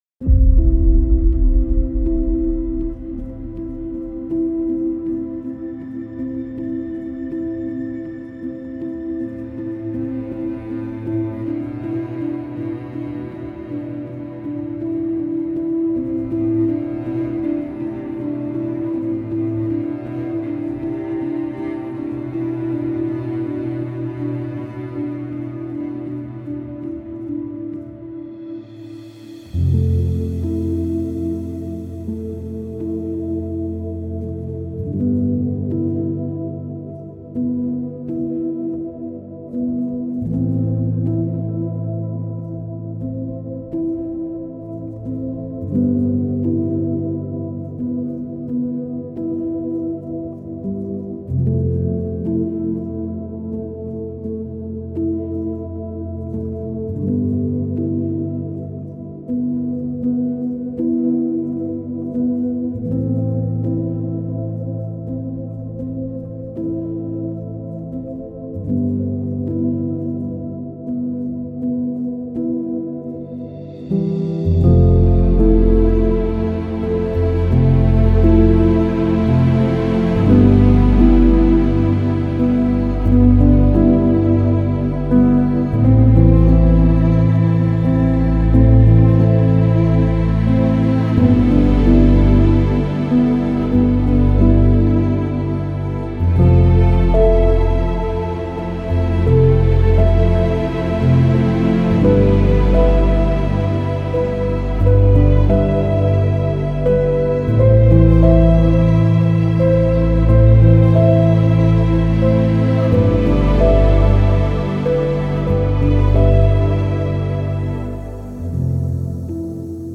عمیق و تامل برانگیز , مدرن کلاسیک , موسیقی بی کلام
موسیقی بی کلام با شکوه موسیقی بی کلام تامل برانگیز